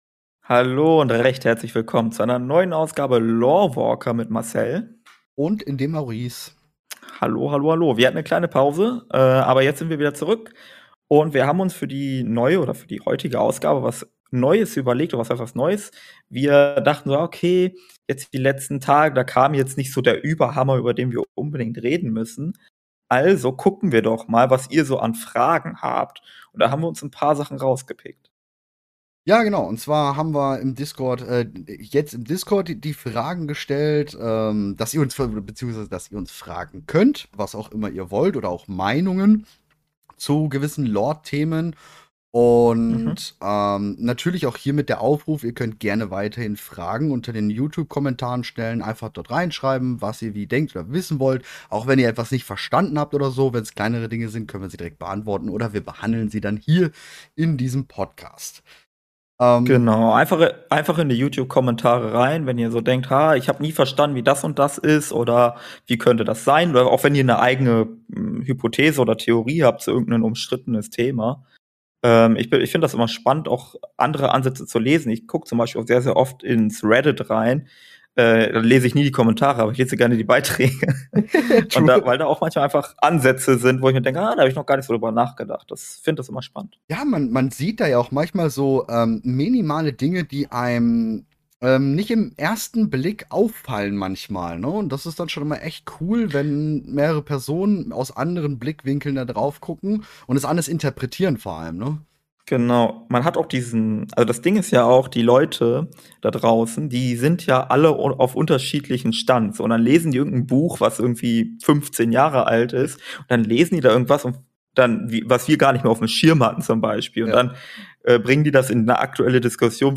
Ihr hattet Fragen und wir hoffentlich Antworten, die Q&A Runde